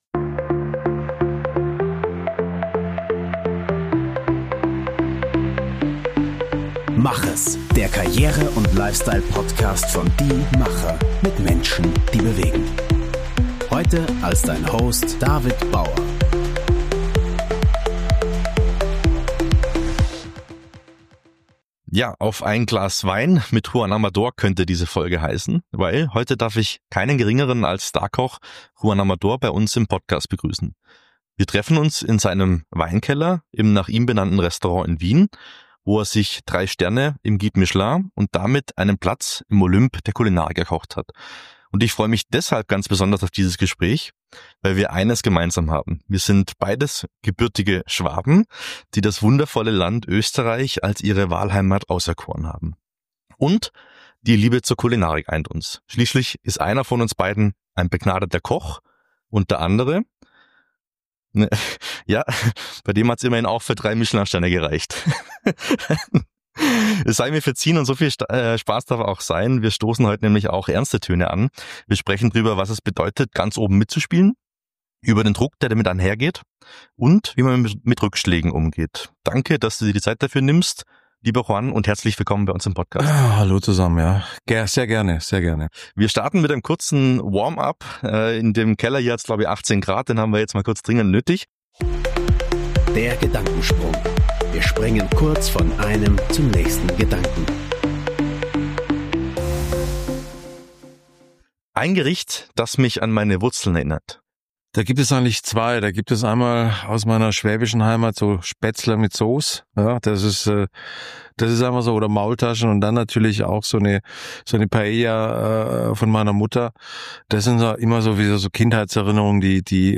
Zu Gast ist Juan Amador, Starkoch und Inhaber seines nach ihm benannten Restaurants in Wien – ausgezeichnet mit 3 Michelin-Sternen. Bei einem Glas Wein in seinem Weinkeller teilt der Ausnahmekoch seinen Weg an die Spitze und die Herausforderungen, die damit verbunden sind. Juan Amador erzählt nicht nur über seine beeindruckende Karriere vom Jungen mit spanischen Wurzeln zum international gefeierten Spitzenkoch, sondern teilt auch offen seine Gedanken zu Perfektion, Fehlerkultur und dem kollegialen Miteinander seiner Profession.